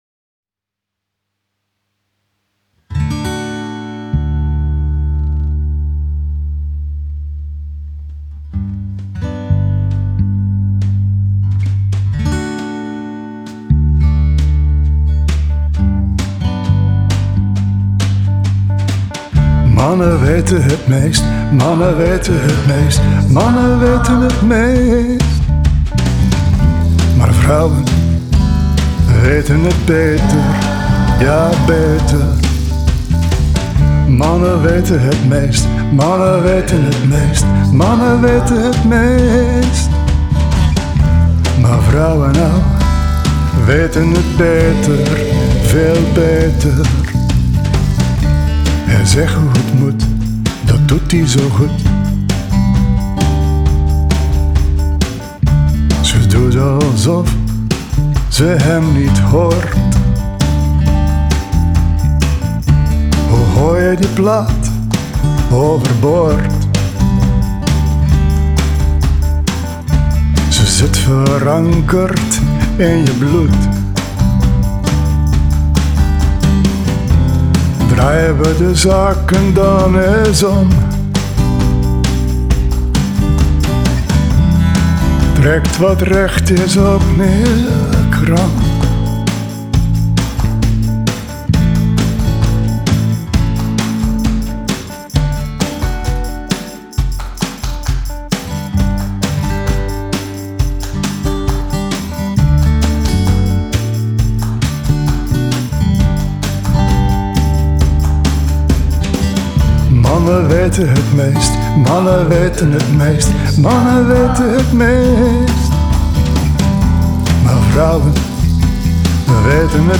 admin Digitale singles